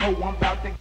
hoe about to kill drop.wav